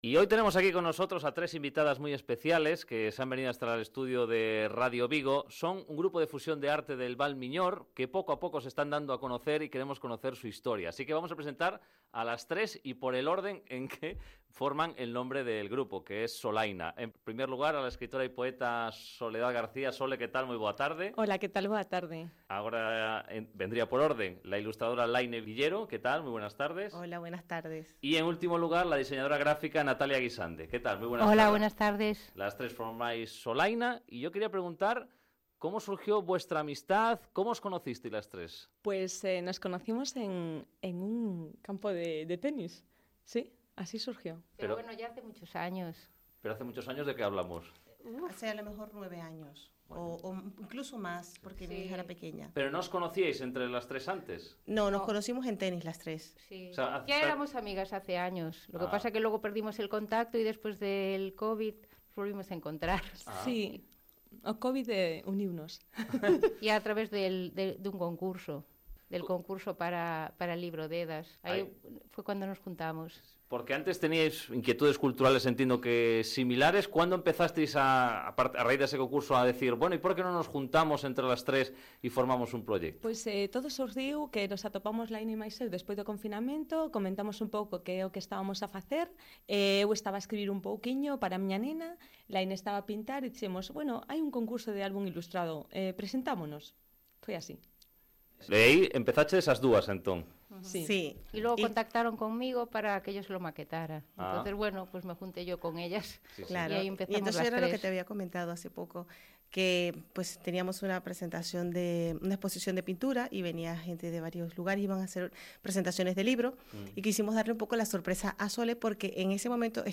DEDAS - Entrevista en Radio Vigo